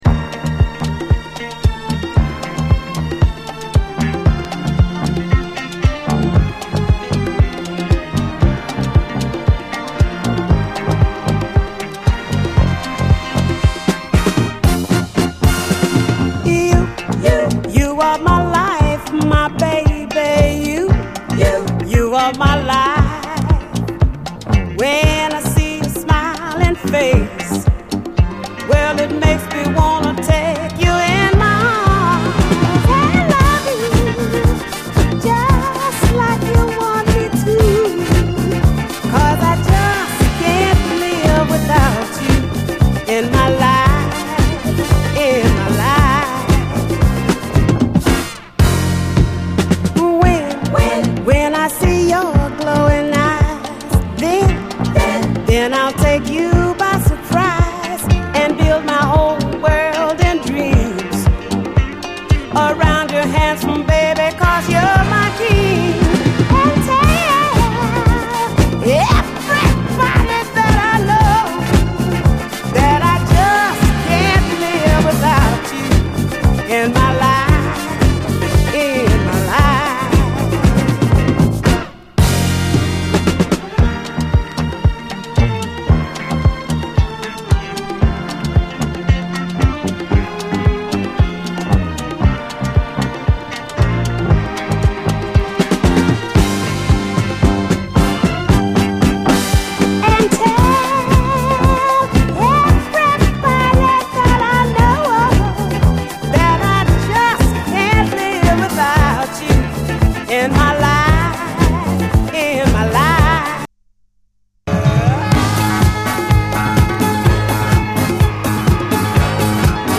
SOUL, 70's～ SOUL, DISCO
切ないメロディーのこみ上げ系メロウ・ダンサー
ズンドコ・ビートの流麗フィリー・ディスコ